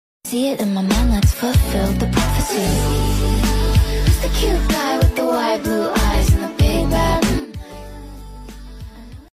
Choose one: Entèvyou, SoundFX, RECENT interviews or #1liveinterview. #1liveinterview